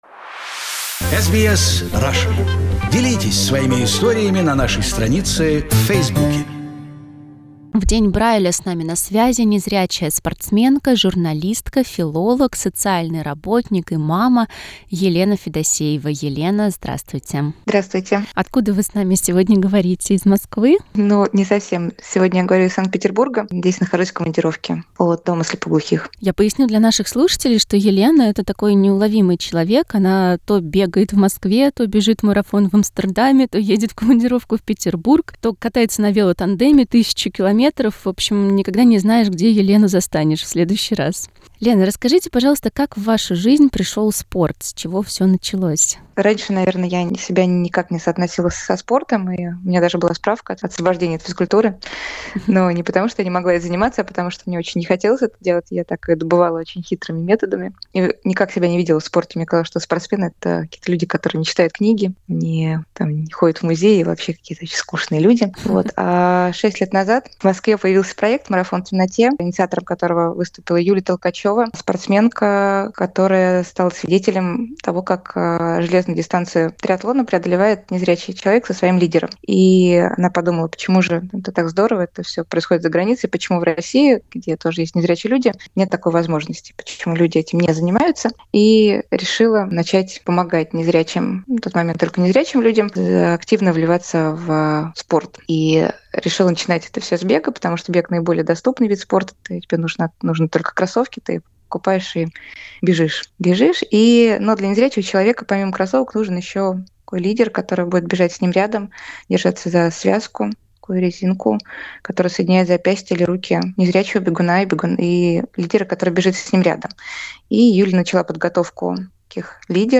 В интервью радиостанции SBS Russian